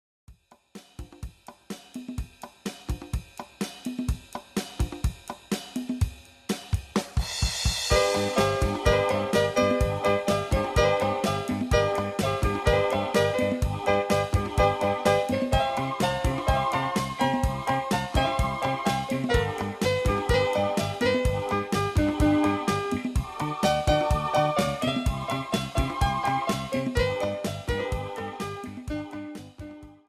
75-Grupero-Disco.mp3